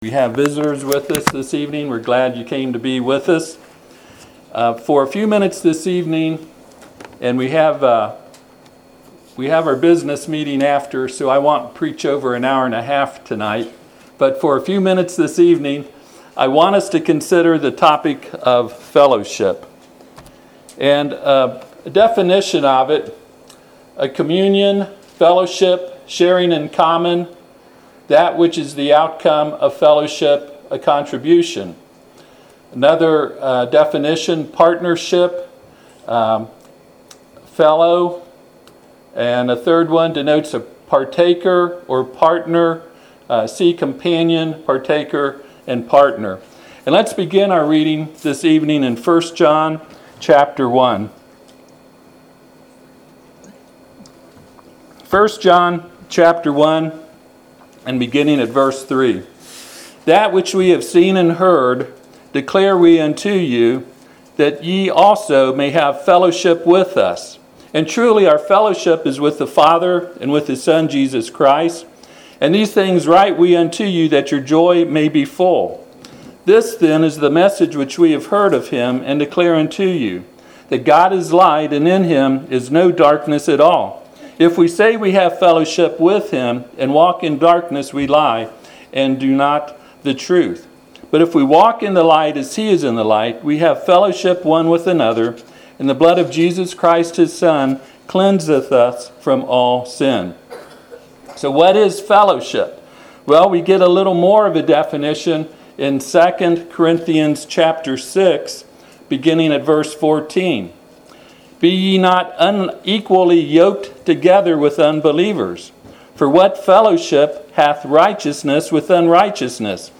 1 John 1:3-7 Service Type: Sunday PM « The Sermon On The Mount.